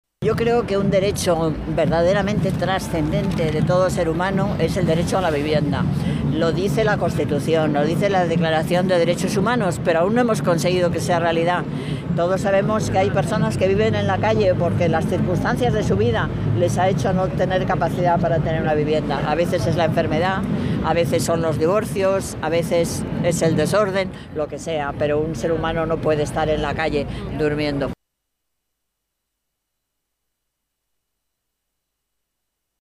El acto de calle
A continuación, la Alcaldesa Manuela Carmena